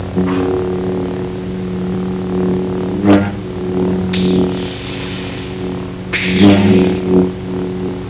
Lightsaber
lightsab[1].wav